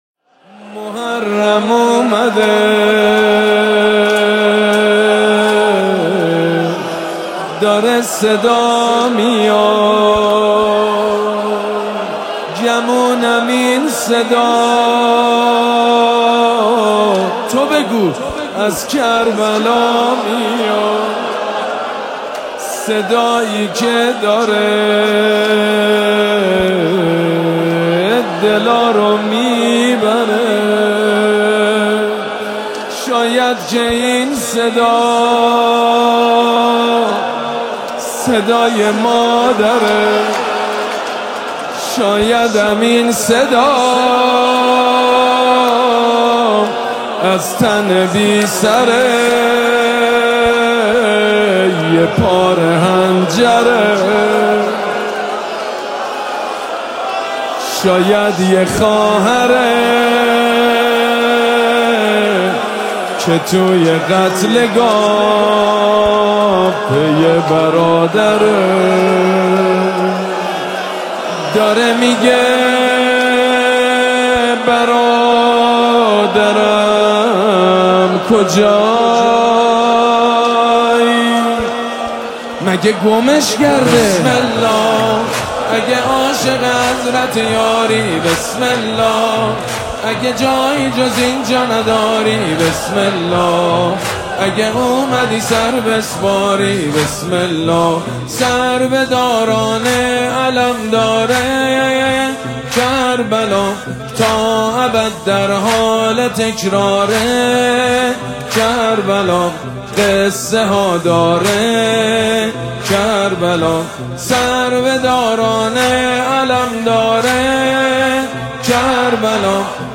مداحی ماه محرم